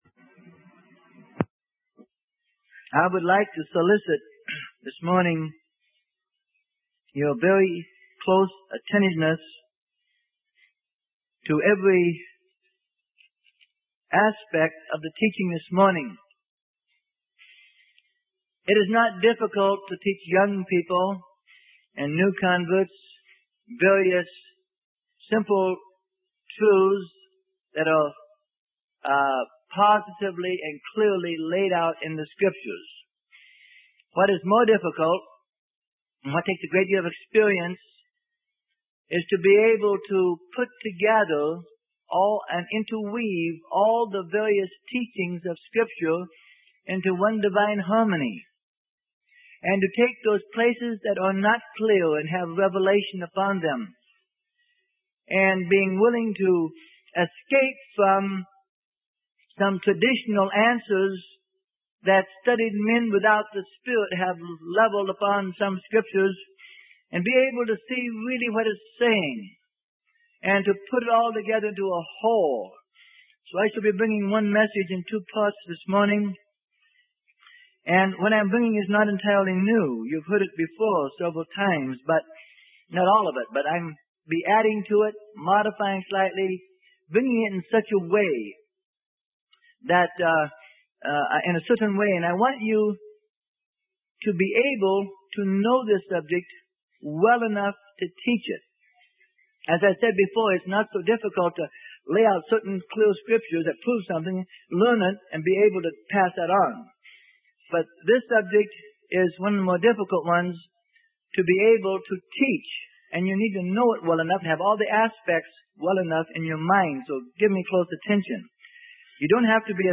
Sermon: Out of the First Adam and Into the Second Adam - Part 1 - Freely Given Online Library